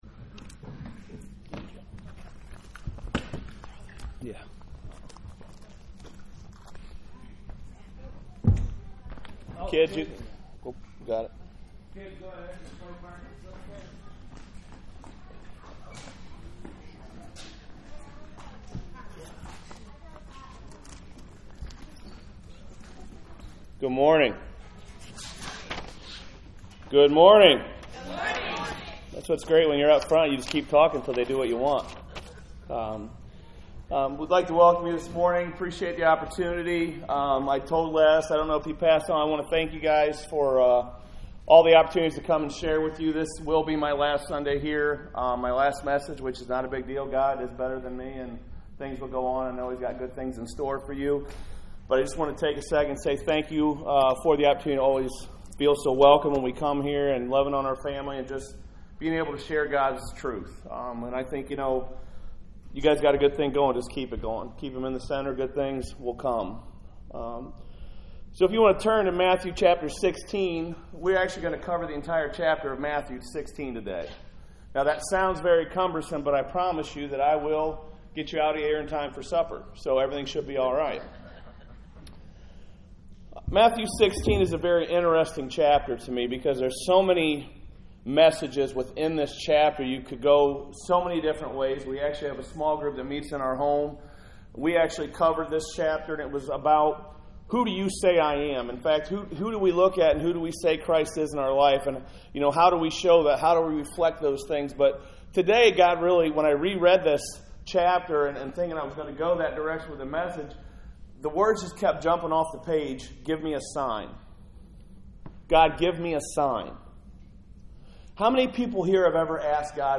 Bible Text: Matthew 16:1-28, Galatians 5:9,1 Corinthians 13 | Preacher